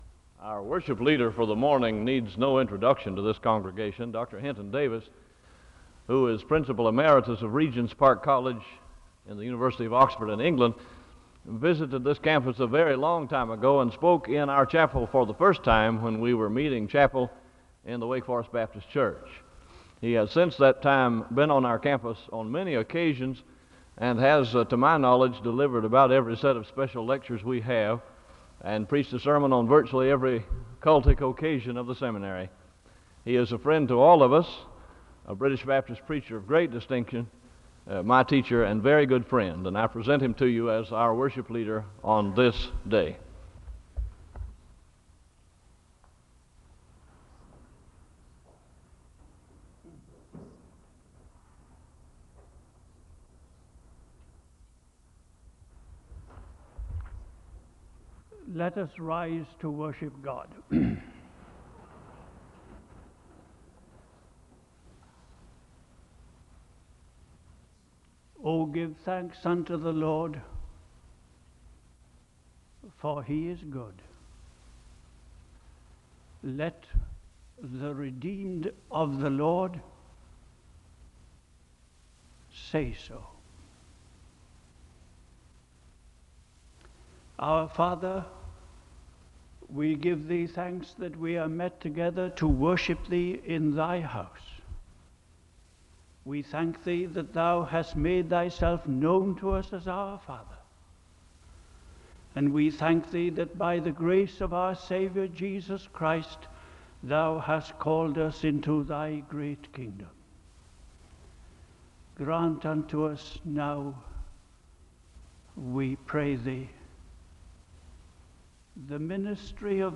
He speaks about being wise for salvation, and he gives a word of prayer (02:35-08:29). The choir sings a song of worship (08:30-11:50).
Wake Forest (N.C.)